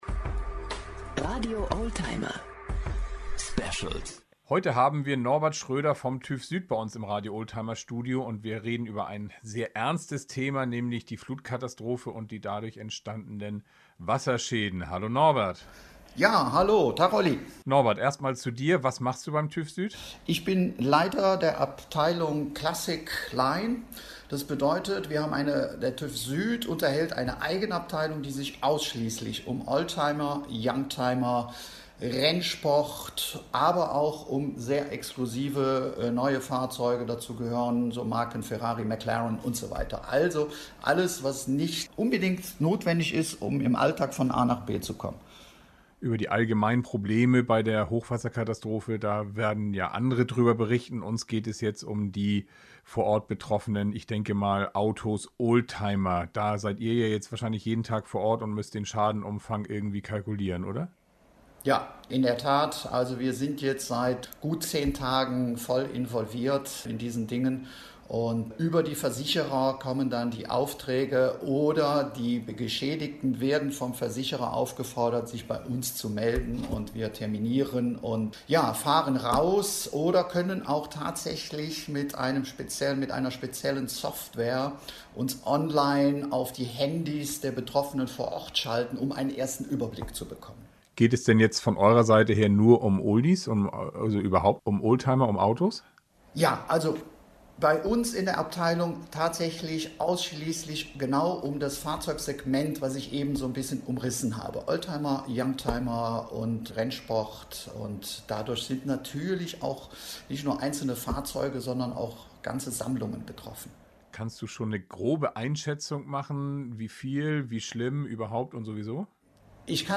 Hört hier das sehr wichtige, informative und interessante Interview im Radio-Oldtimer-Podcast, klick.